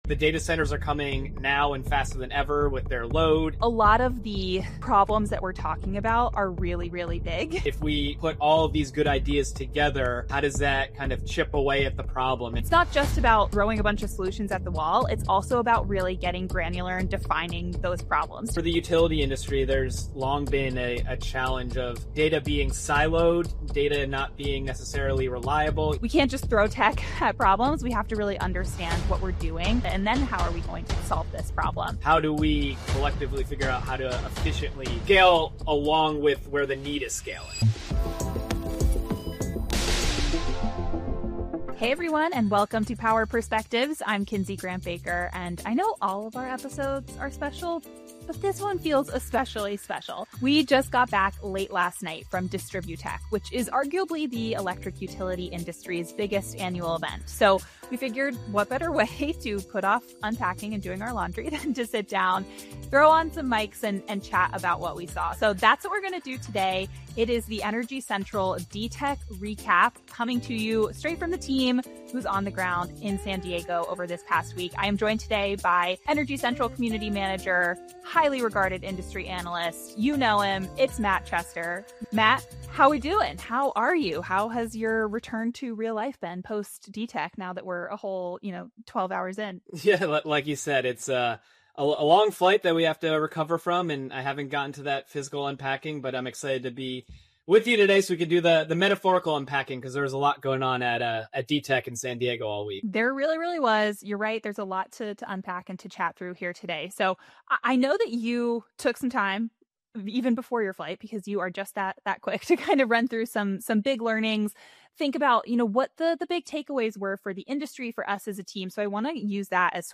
If you missed the chance to be in San Diego with the movers and shakers of the power sector at DTech 2026, consider this conversation the antidote to your FOMO.